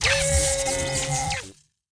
Player Zap Death Sound Effect
Download a high-quality player zap death sound effect.
player-zap-death-2.mp3